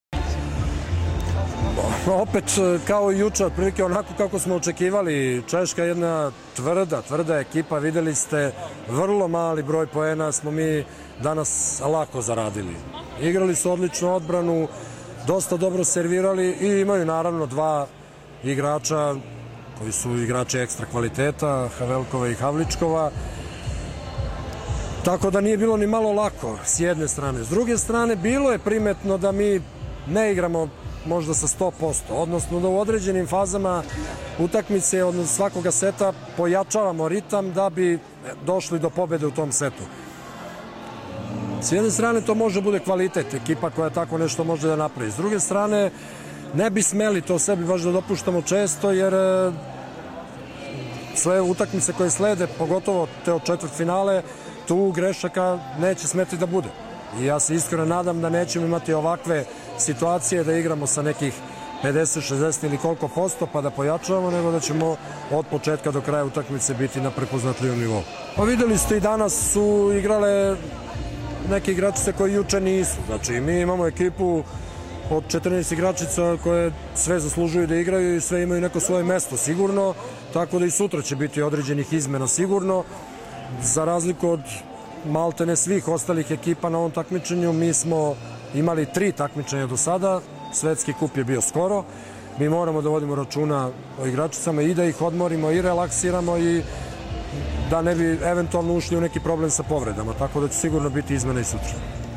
IZJAVA ZORAN TERZIĆA